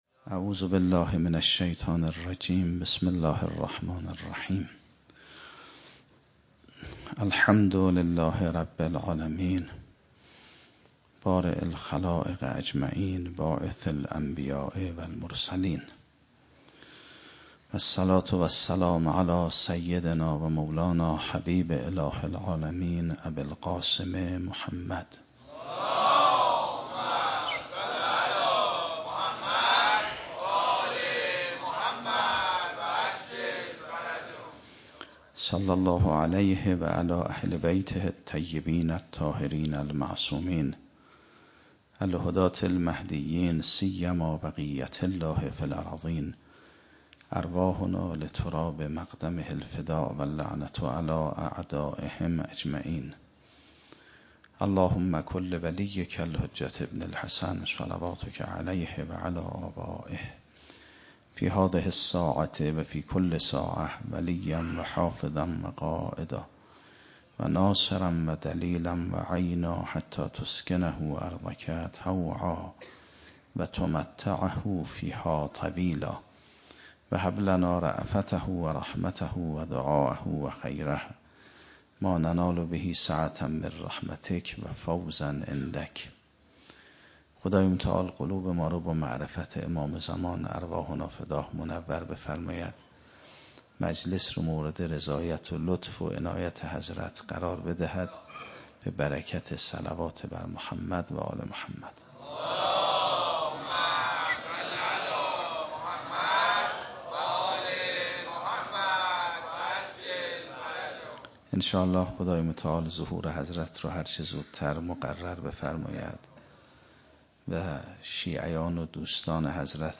30 بهمن 96 - حسینیه حق شناس - سخنرانی